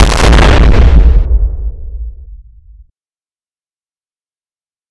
Medium Explosion
bang bomb boom distortion dynamite explosion grenade loud sound effect free sound royalty free Memes